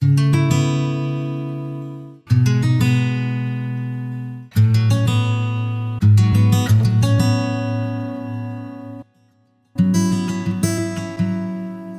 阳光灿烂的日子 快乐吉他
标签： 80 bpm Trap Loops Guitar Acoustic Loops 4.05 MB wav Key : B FL Studio
声道立体声